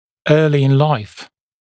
[‘ɜːlɪ ɪn laɪf][‘ё:ли ин лайф]в раннем возрасте